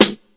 • 00's Rap Acoustic Snare Sound G# Key 267.wav
Royality free snare drum tuned to the G# note. Loudest frequency: 4023Hz
00s-rap-acoustic-snare-sound-g-sharp-key-267-wZf.wav